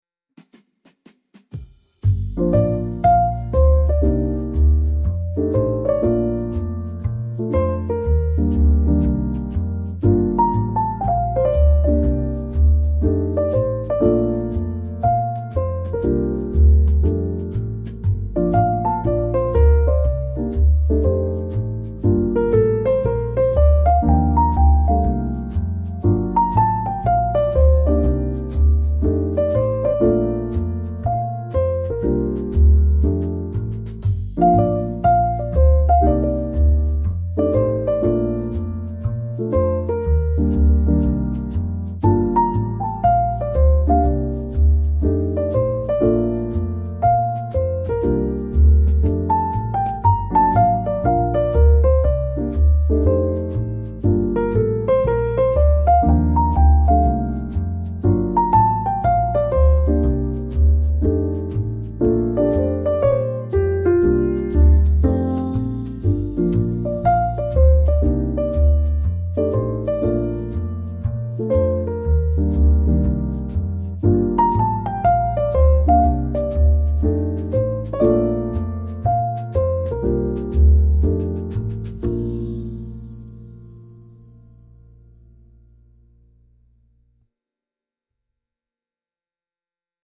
elegant jazz piano trio with brushed drums and upright bass